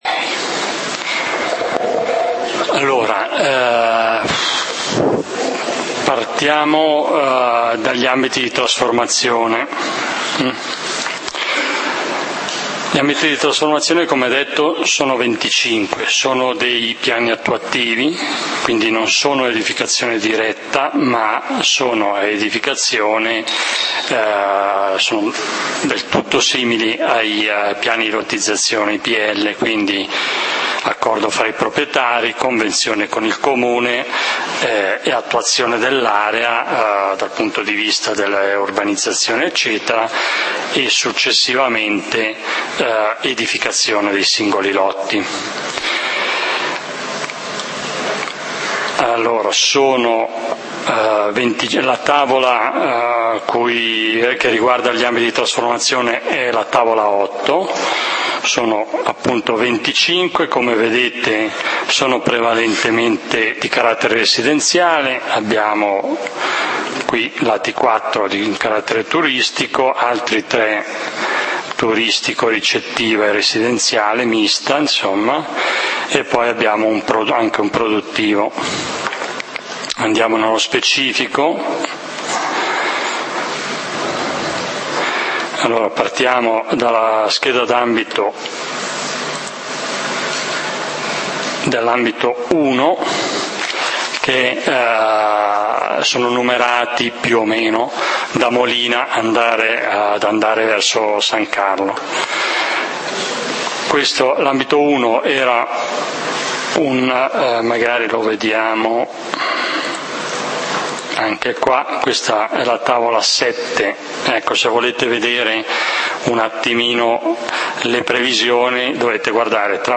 Assemblea pubblica del comunale di Valdidentro del 04 Ottobre 2013